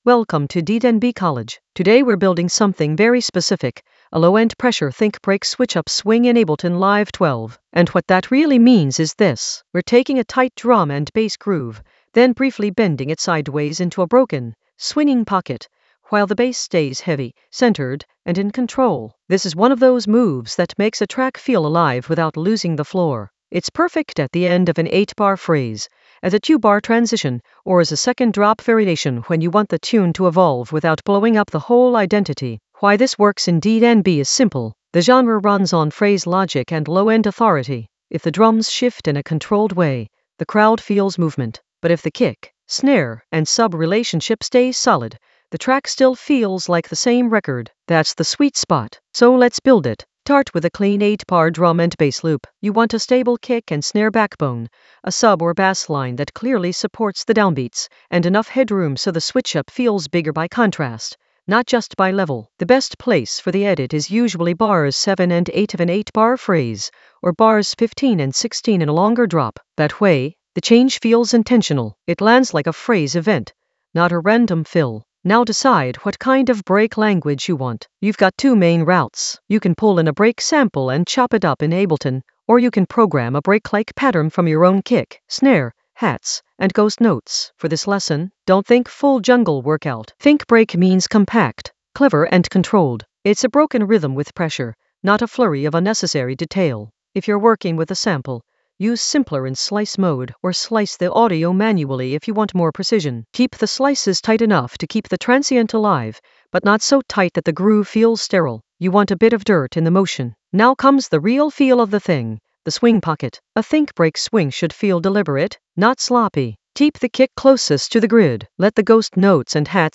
An AI-generated intermediate Ableton lesson focused on Low-End Pressure approach: a think-break switchup swing in Ableton Live 12 in the Edits area of drum and bass production.
Narrated lesson audio
The voice track includes the tutorial plus extra teacher commentary.